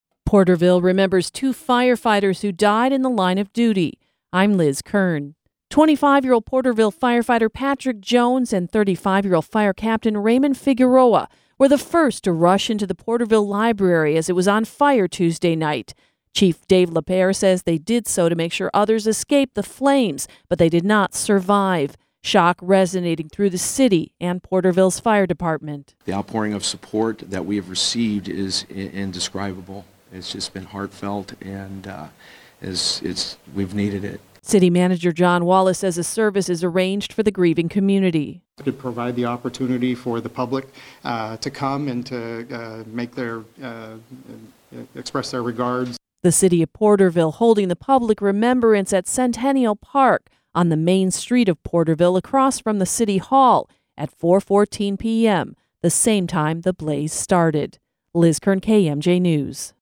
LK-WEB-PORTERVILLE-FIRE-CEREMONY.mp3